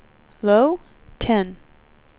speech / tts / prompts / voices